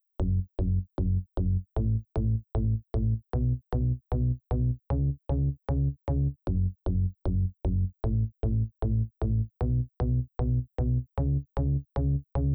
ベース
特徴はアタックが強く短い音裏打ちしていること。
更にこのベースはFM変調を用いて「ギョン」とか「ミョン」という感じの独特なニュアンスが付いています。